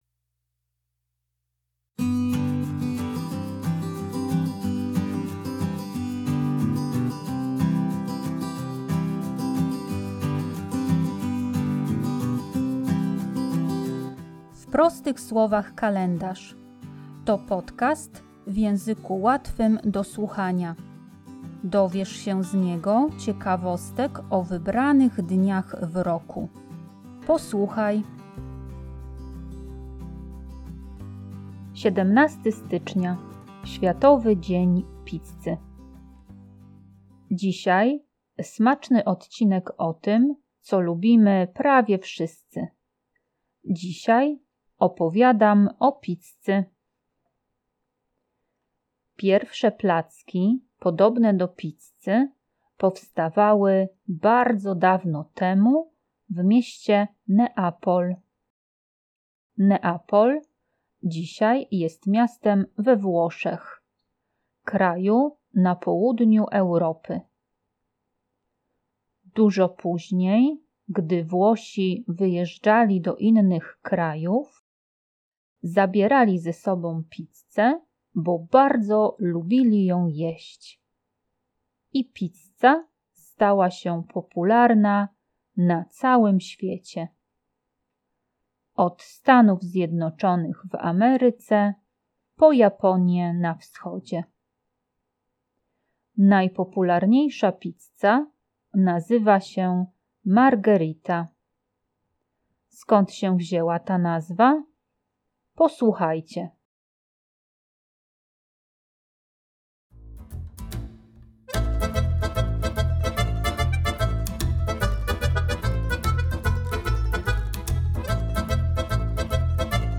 W podcaście usłyszycie dźwięki włoskiego tańca ludowego Tarantela z Neapolu.